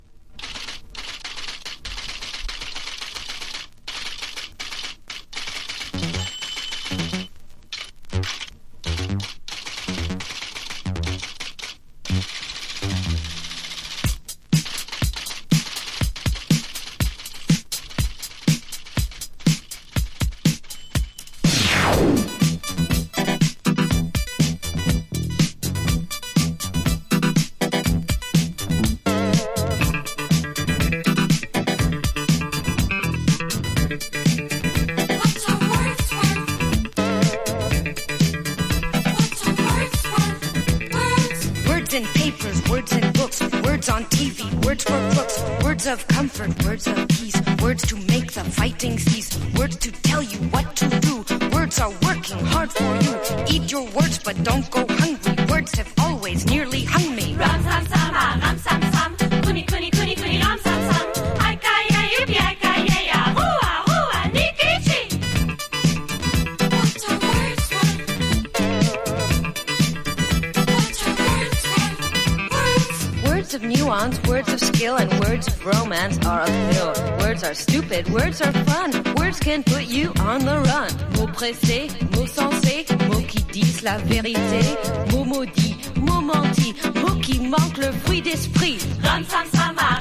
• HOUSE